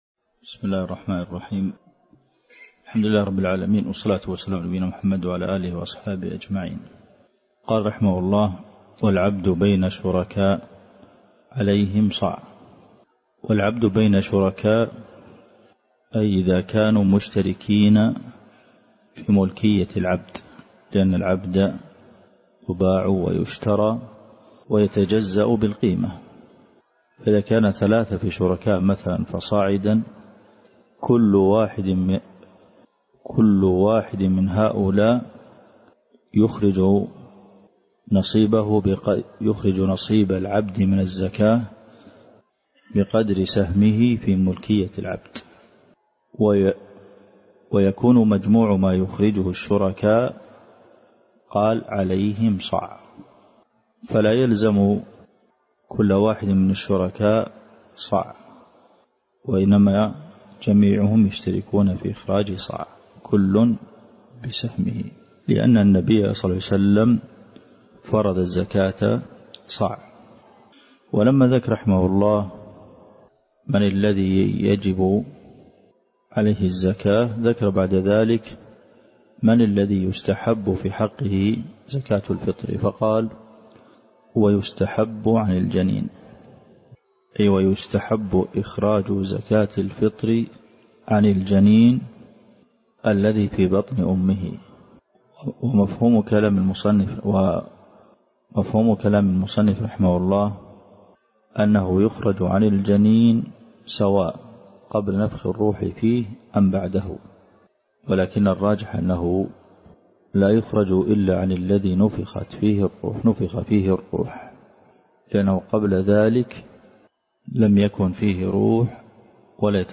الدرس (20) شرح زاد المستقنع دورة في فقه الصيام - الشيخ عبد المحسن القاسم